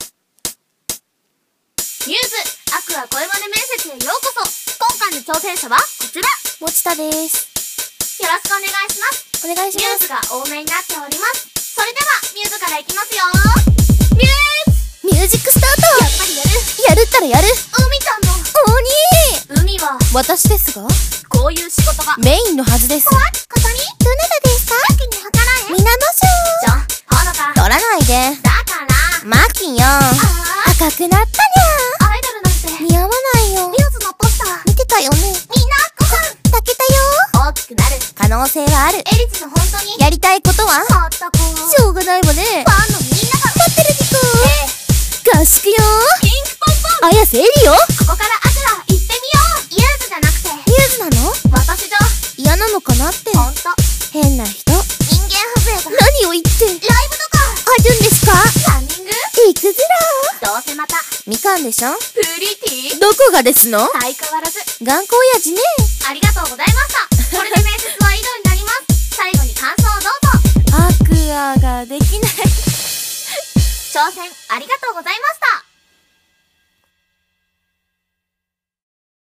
µ’sAqours声真似面接